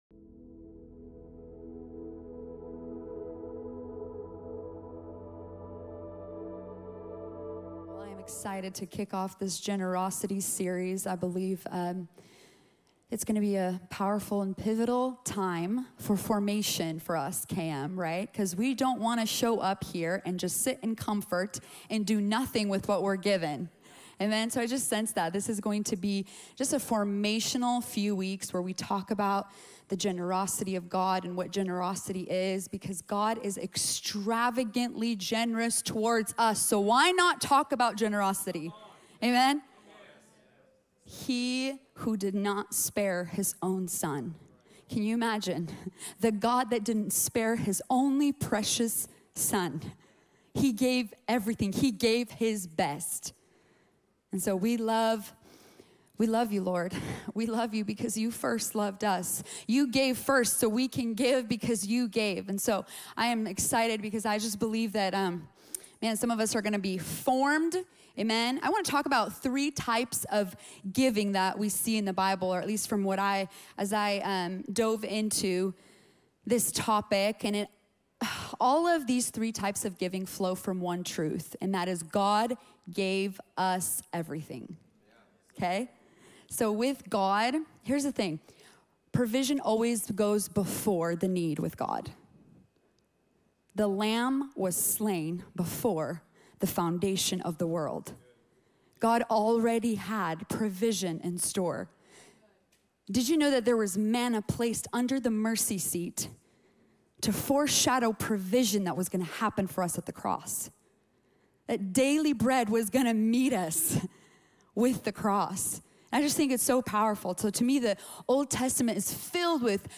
A collection of our Sunday Messages from Kingdom Movement.